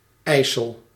The IJssel (Dutch: [ˈɛisəl]
Nl-IJssel.ogg.mp3